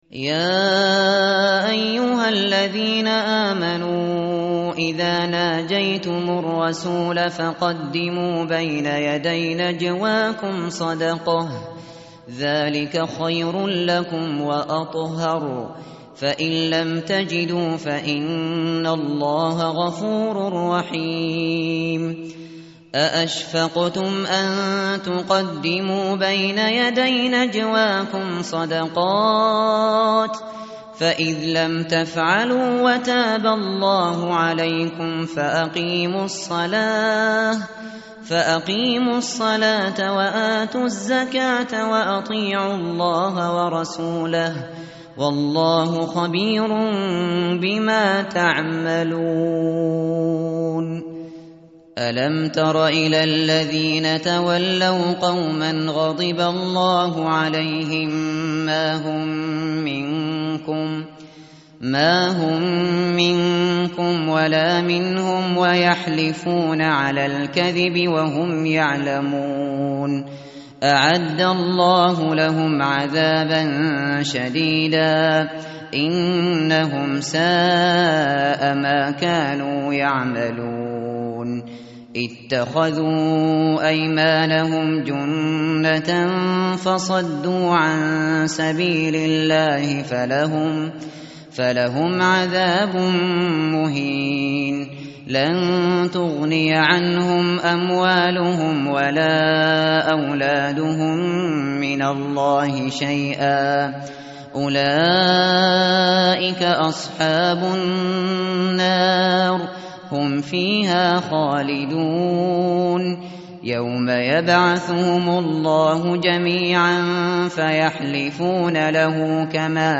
tartil_shateri_page_544.mp3